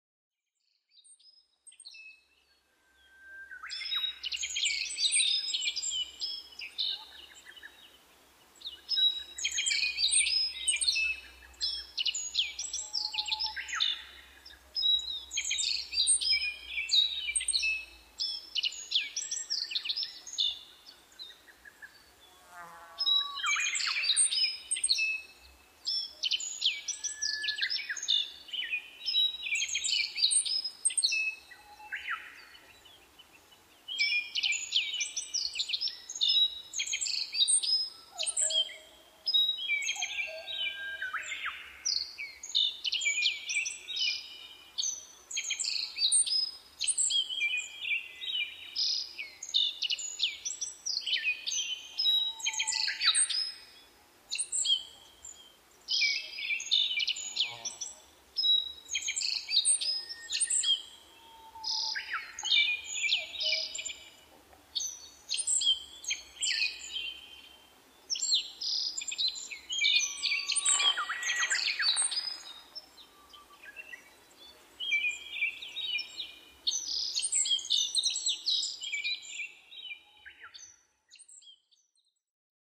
アオジ　Emberiza spodocephalaホオジロ科
奥日光戦場ヶ原　alt=1400m
Mic: Panasonic WM-61A  Binaural Souce with Dummy Head
複数のアオジが鳴き交わしています。
他の自然音：ウグイス、カッコウ